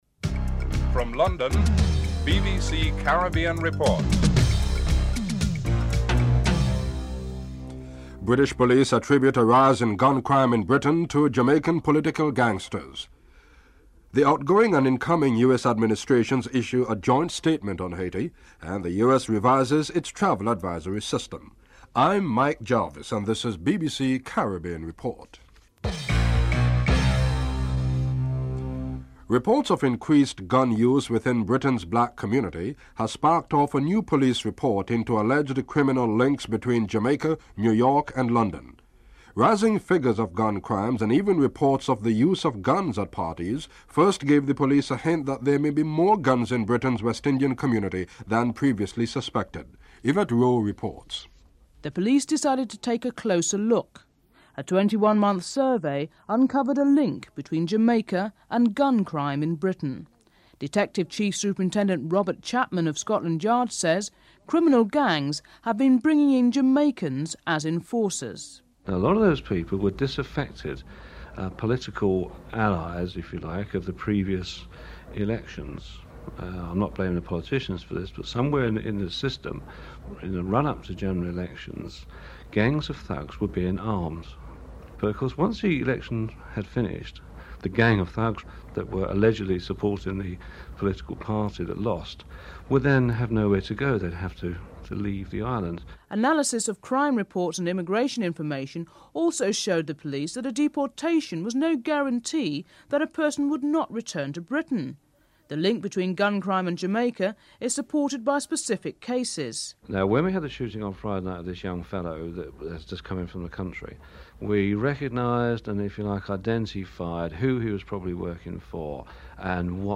Headlines - (00:27).